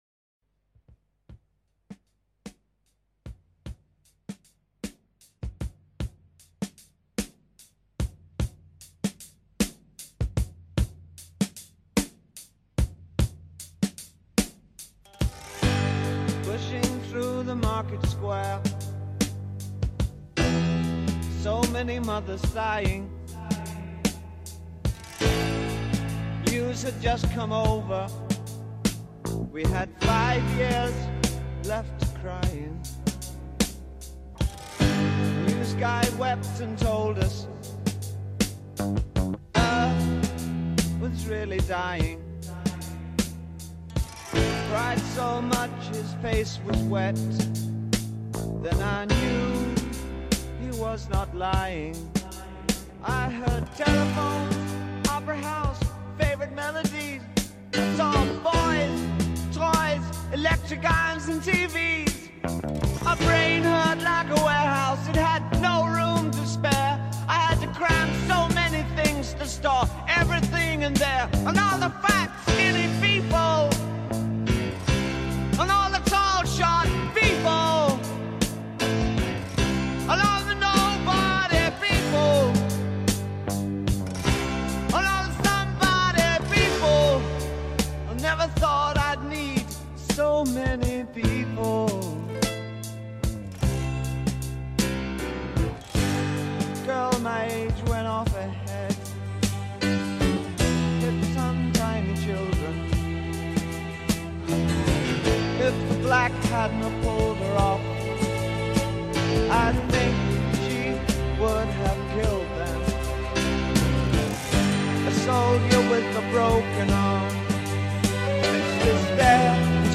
Glam Rock, Art Rock